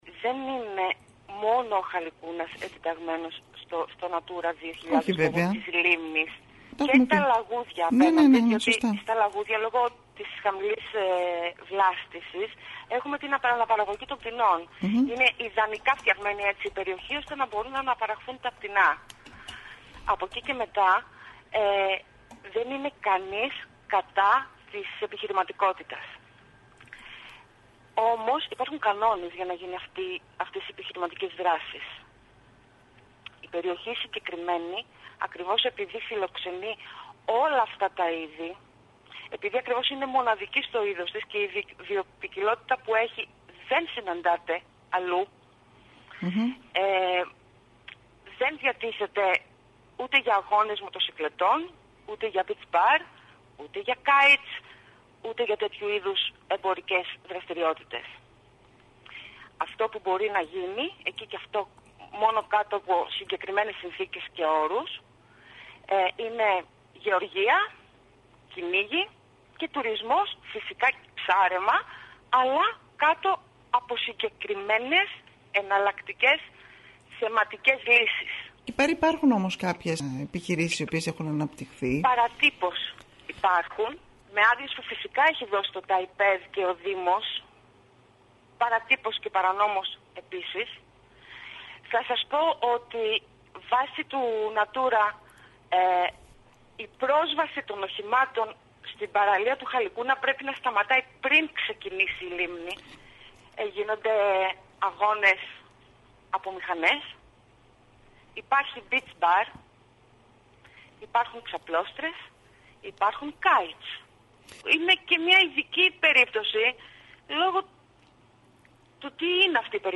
απο το κίνημα πολιτών για την προστασία του Χαλικούνα και της λίμνης Κορρισίων μίλησε στην ΕΡΤ Κέρκυρας.